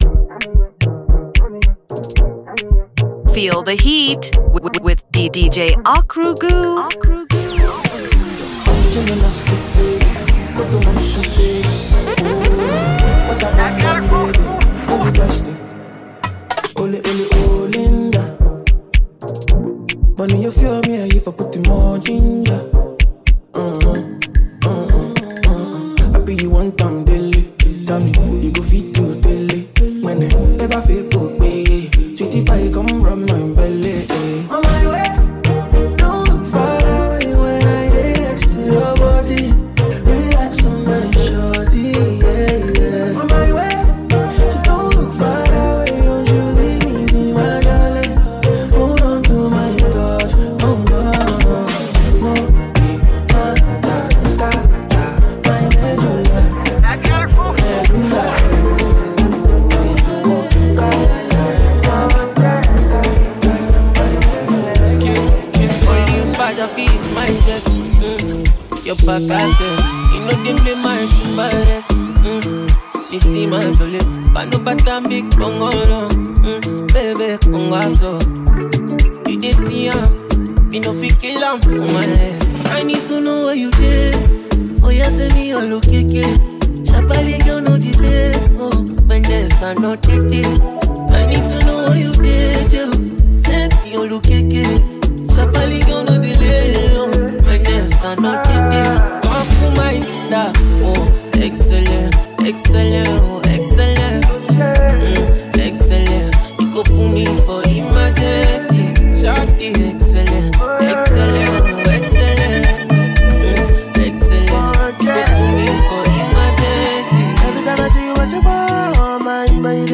This is a mixtape you can't miss if you like Afrobeat music.